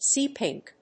アクセントséa pìnk